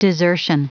Prononciation du mot desertion en anglais (fichier audio)
Prononciation du mot : desertion